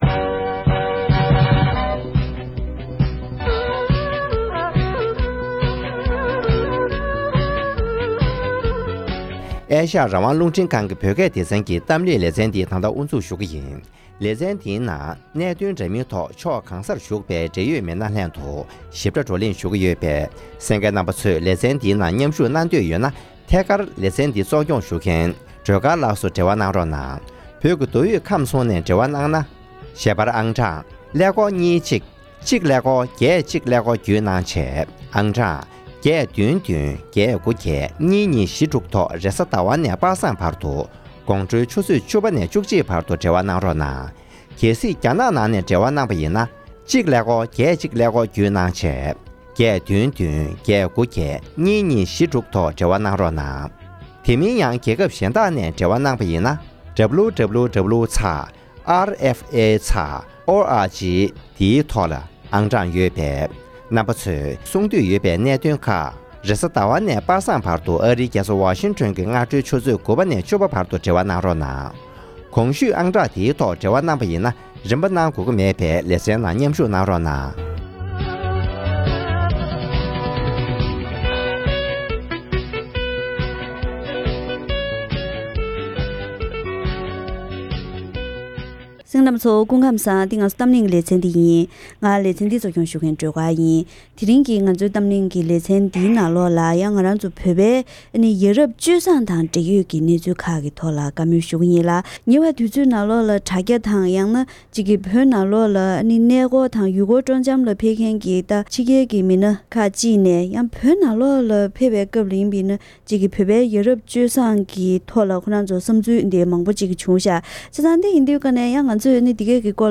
༄༅། །དེ་རིང་གི་གཏམ་གླེང་ལེ་ཚན་ནང་། བོད་དུ་སྐྱོད་མཁན་རྒྱ་མི་རིགས་ཀྱི་ནང་པ་དང་ཡུལ་ སྐོར་བ་མང་དུ་འགྲོ་བཞིན་ཡོད་པས། ཕྱི་མིའི་མིག་ནང་བོད་མིར་མཐོང་ཚུལ་འདྲ་མིན་ཡོང་གི་ཡོད་པ་དང་། དམིགས་བསལ་བོད་པའི་ཀུན་སྤྱོད་ཐད་ལྟ་ཚུལ་ཡོང་གི་ཡོད་པ་དེའི་ཐོག་བགྲོ་གླེང་ཞུས་པ་ཞིག་གསན་རོགས་གནང་།